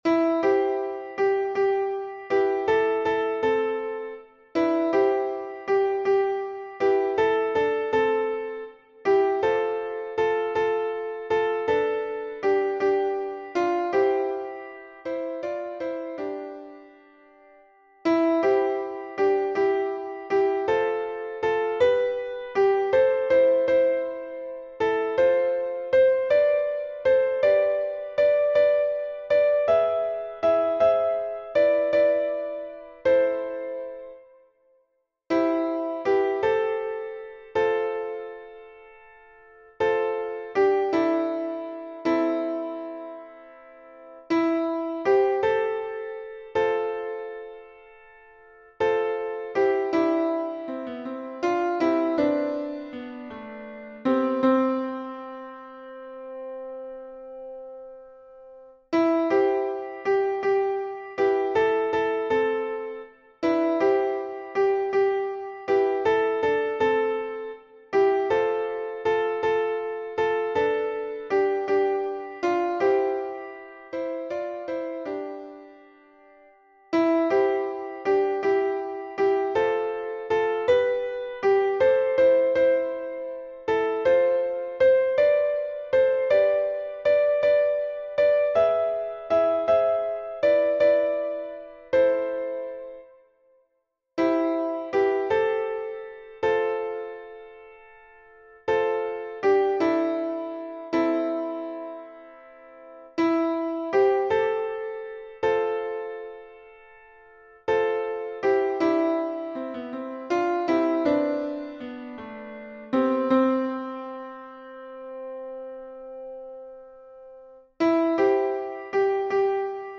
Voix principale et autres voix en arrière-plan